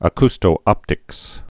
(ə-kstō-ŏptĭks)